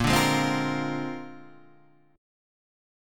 A#9 chord {x 1 0 1 1 1} chord